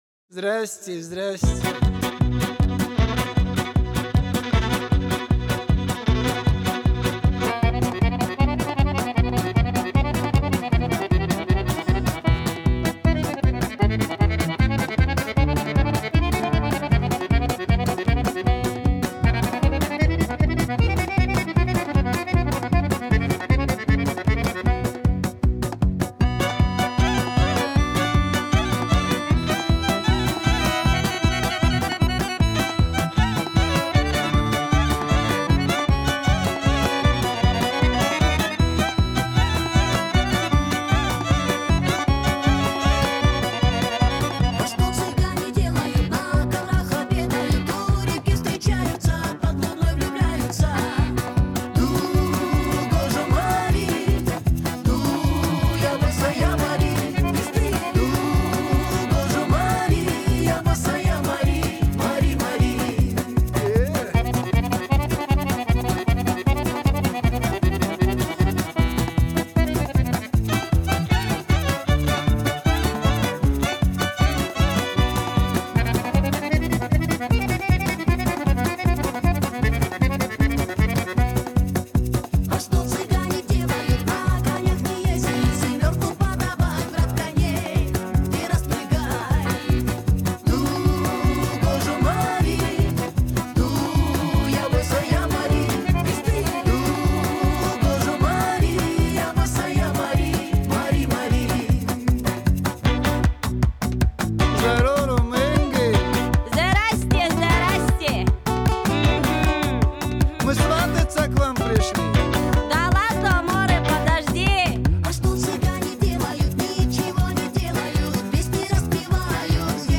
минусовка версия 23270